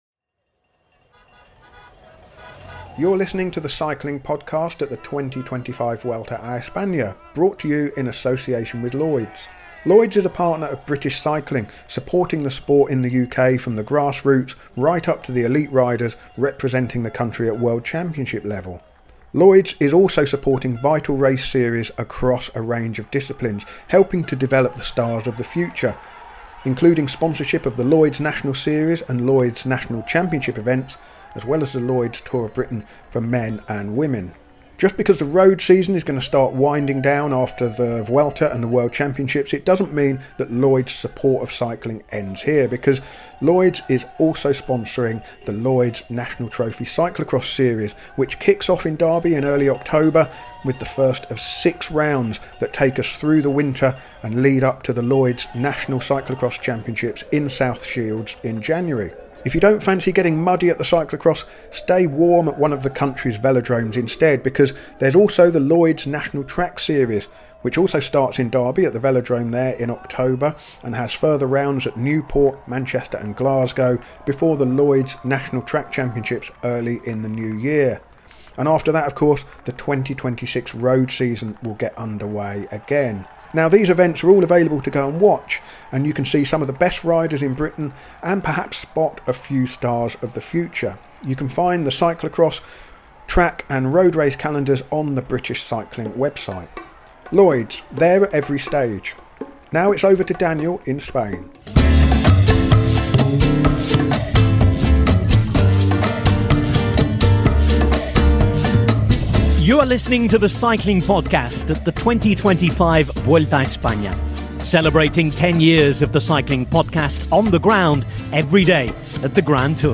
The end is almost in sight — this is our 20th daily podcast from the Vuelta a España 2025, recorded on the road as the race heads towards Madrid. Each episode brings race analysis, rider interviews, and daily postcards from Spain.